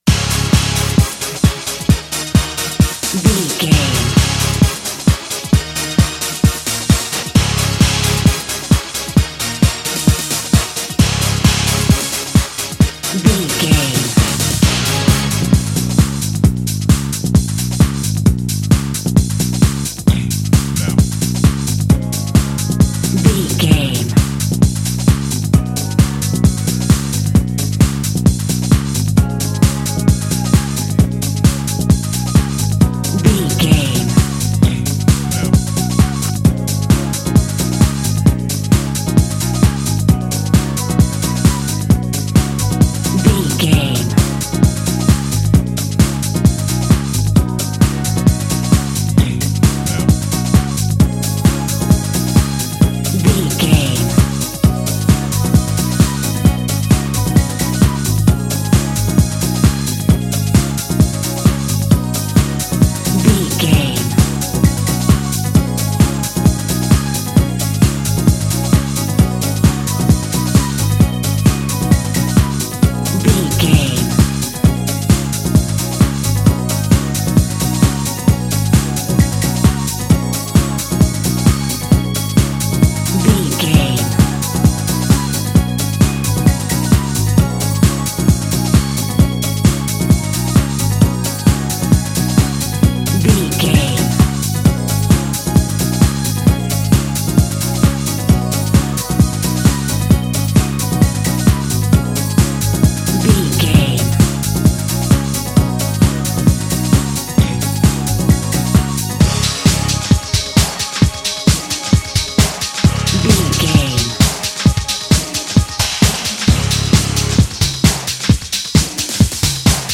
Uplifting
Ionian/Major
drum machine
synthesiser
bass guitar